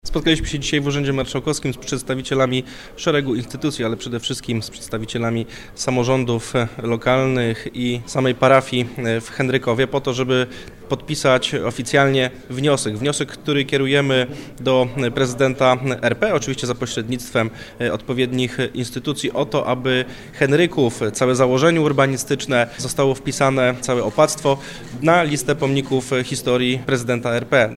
Taki dokument podpisano podczas konferencji prasowej w Urzędzie Marszałkowskim. Tłumaczy Grzegorz Macko, wicemarszałek Województwa Dolnośląskiego.